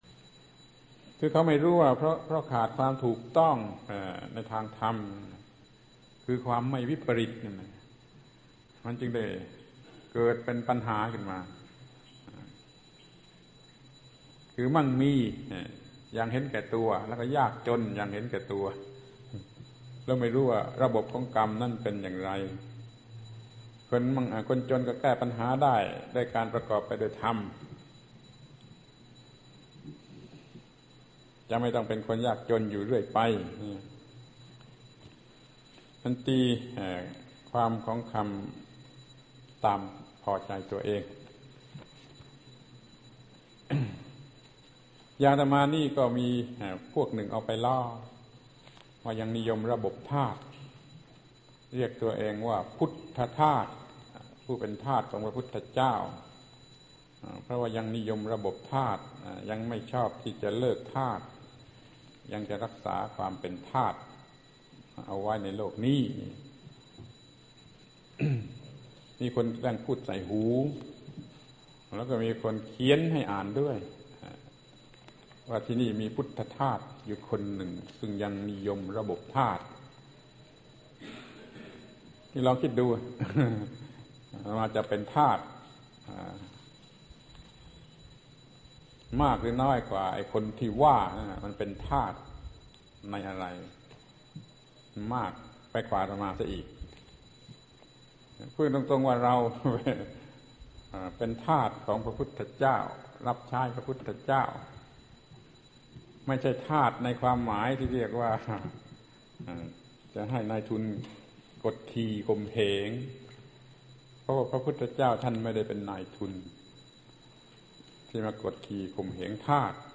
พระธรรมโกศาจารย์ (พุทธทาสภิกขุ) - แสดงธรรมล้ออายุ ปี 2519 ครั้ง 1 ความวิปริต (ต่อ)